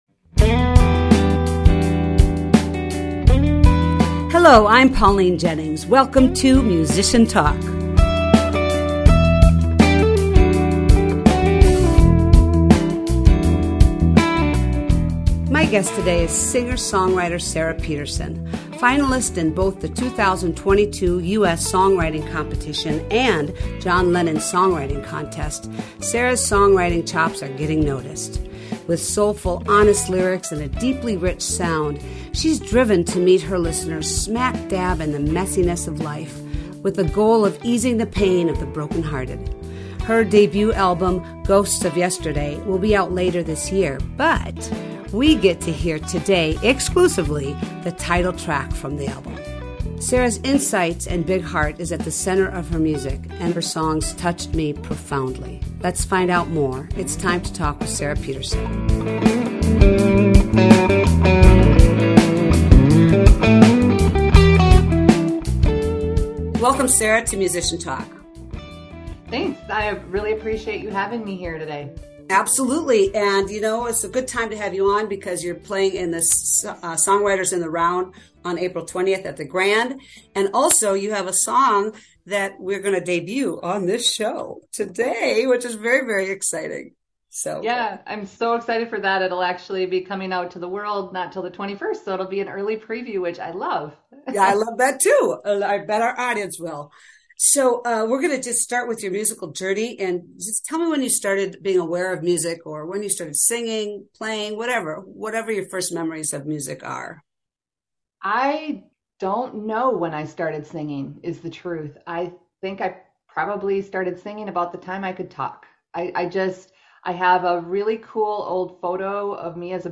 With aching lyrics and a rich sound, she hopes to ease broken hearts with her music. Find out more about her musical journey, listen and discuss a couple originals from her upcoming album, delve into the music quote of the week, dish about her best gig/worst gig, and so much more!